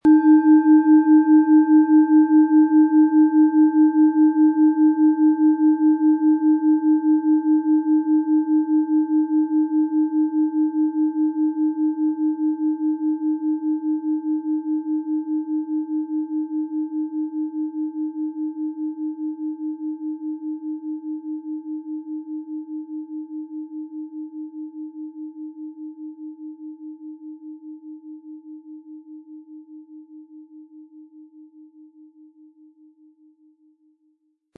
Planetenton
Wie klingt diese tibetische Klangschale mit dem Planetenton Eros?
Um den Original-Klang genau dieser Schale zu hören, lassen Sie bitte den hinterlegten Sound abspielen.
SchalenformOrissa
MaterialBronze